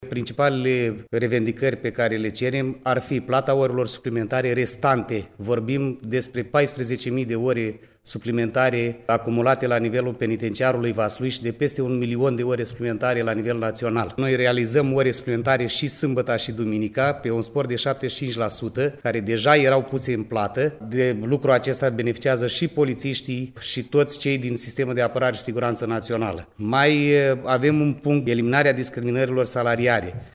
a prezentat, într-o conferinţă de presă, revendicările salariaţilor şi a precizat că acţiunile de protest vor continua, conform unui calendar stabilit la nivel naţional.